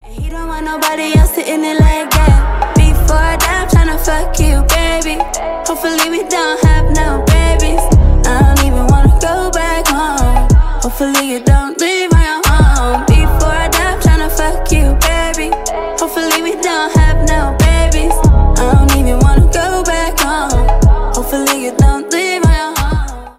Ремикс
Поп Музыка